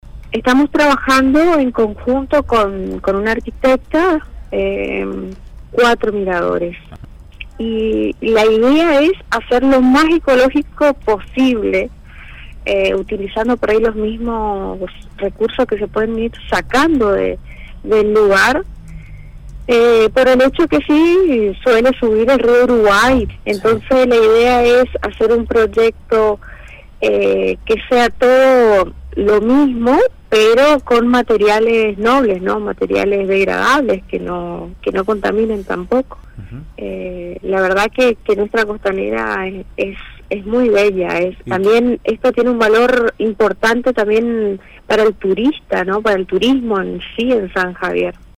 Concejales de San Javier presentaron un proyecto para realizar unos miradores ecológicos para la Puesta en Valor del paisaje con vista al Río Uruguay y también generar conciencia sobre el cuidado de la naturaleza, comentó la concejal de la mencionada localidad, María Gutiérrez, en el programa Provincia Ahora de Radio LT 17.